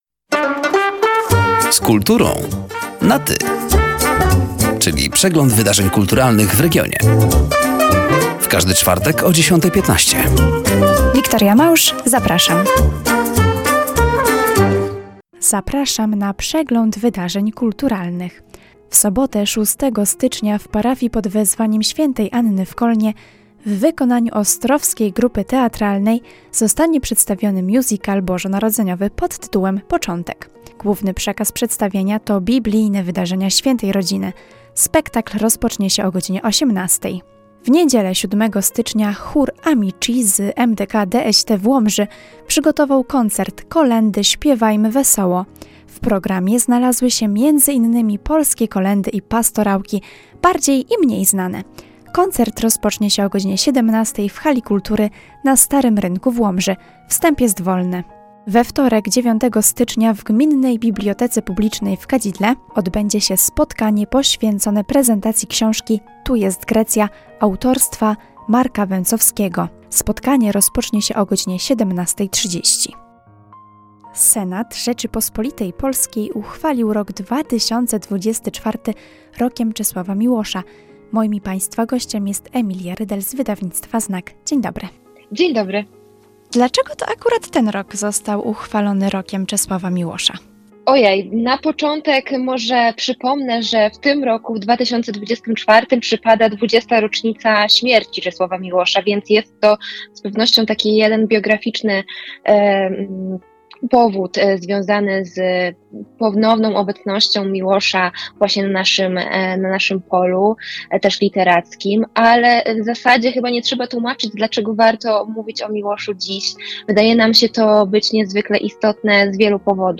Zapraszamy do wysłuchania rozmowy oraz zapoznania się z wydarzeniami kulturalnymi: